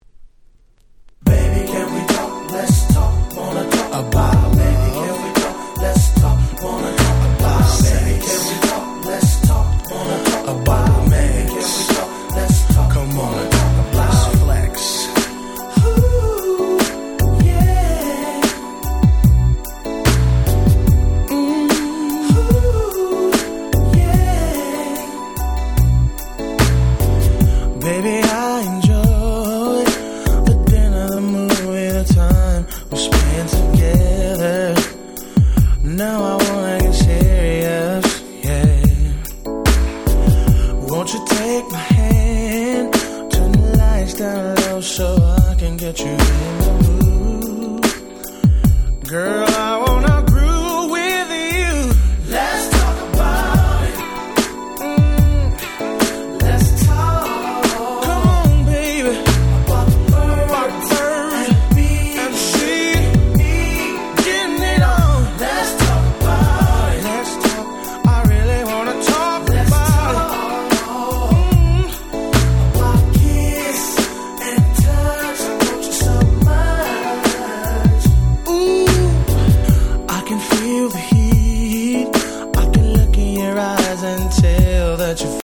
【Media】Vinyl 12'' Single
94' Very Nice R&B / Slow Jam !!
超絶激甘、完全18禁！！
こんな最高のSlowバラード、なかなかございません！！